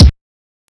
KICKWOBBLE.wav